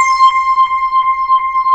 SWEEP   C5-L.wav